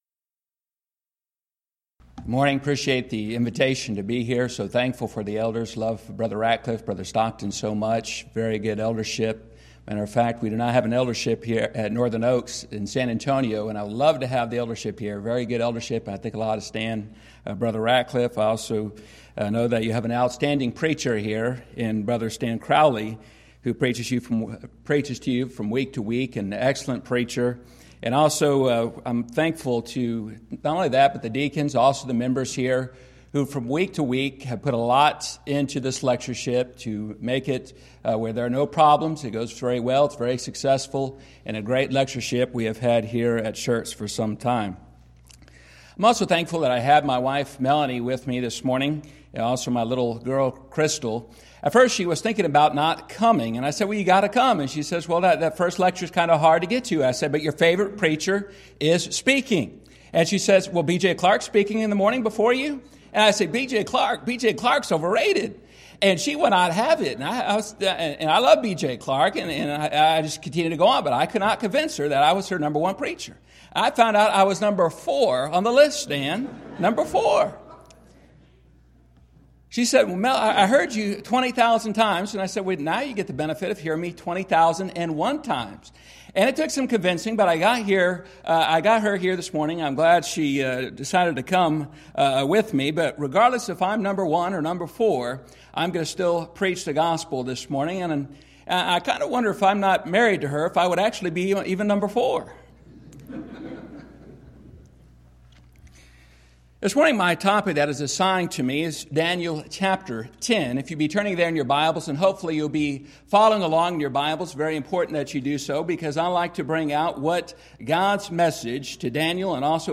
Event: 11th Annual Schertz Lectures Theme/Title: Studies in Daniel